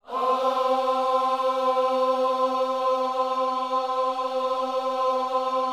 OHS C 4D.wav